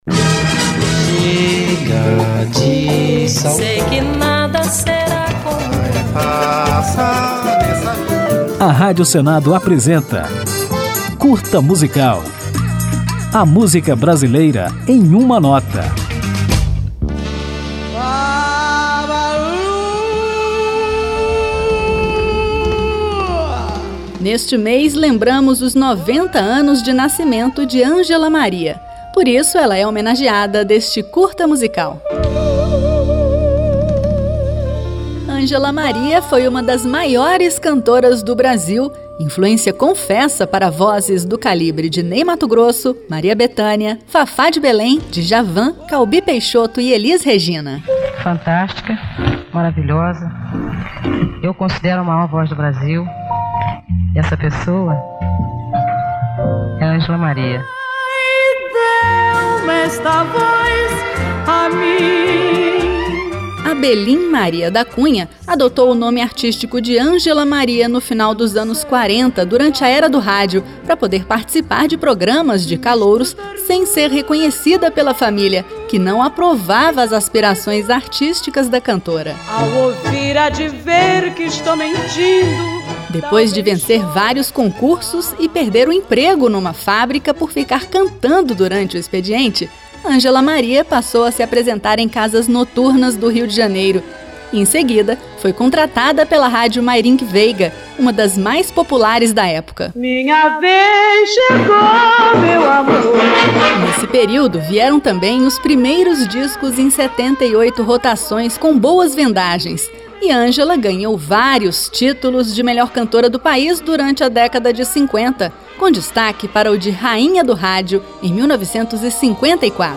Depois de saber um pouco sobre a vida e a obra da cantora, você ouve Angela Maria com Gente Humilde, a canção que ela mais gostava de cantar.